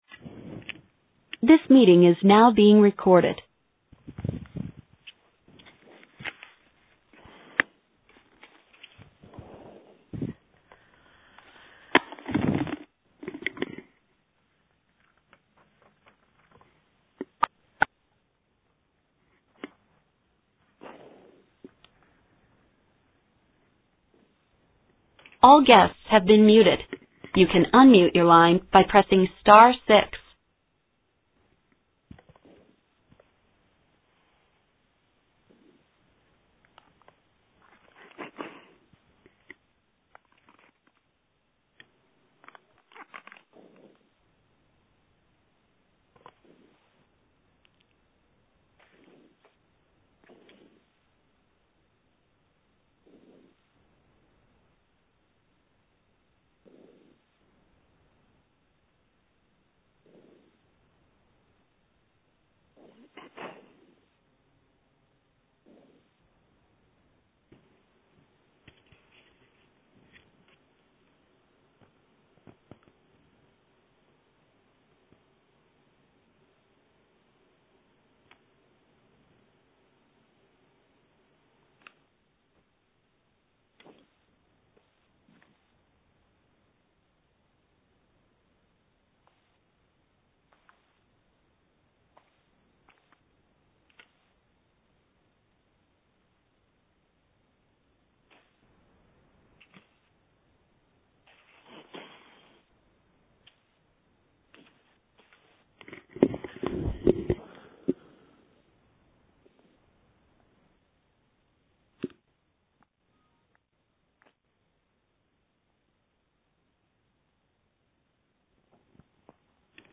In conjunction with the release of the report, the LAC held a recorded teleconference highlighting the key issues.